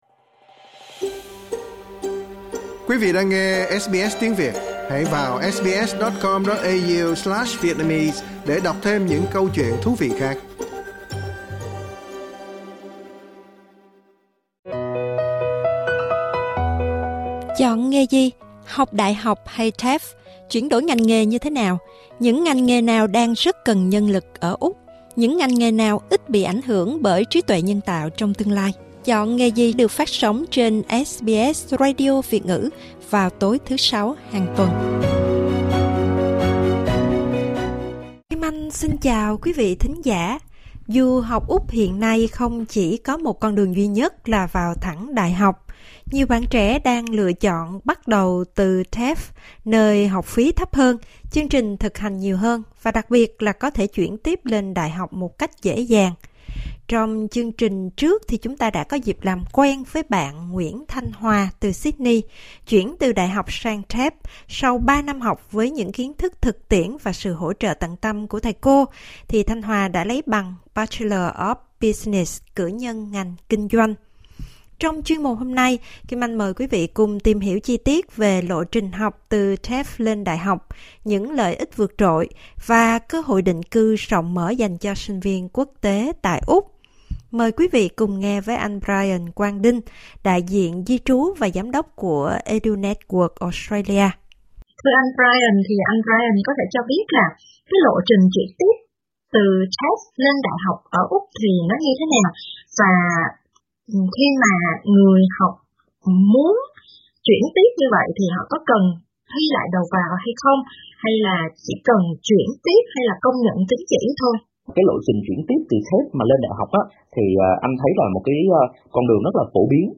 Mời quý vị nhấn vào phần Audio để nghe toàn bộ cuộc trò chuyện